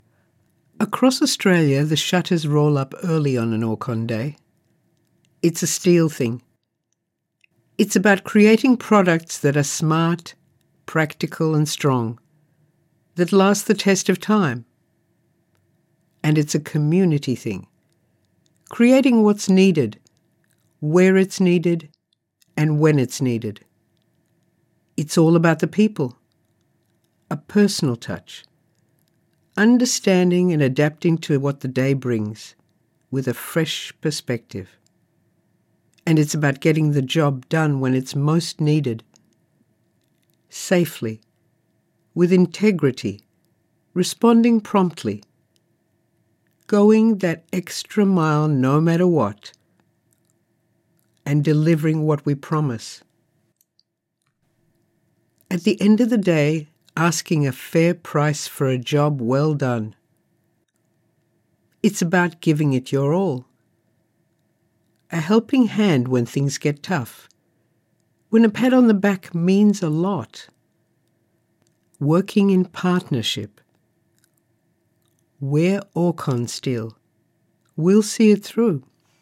Female
English (Australian)
My voice overs are confident, warm, conversational, expressive, engaging, versatile and clear.
Explainer Videos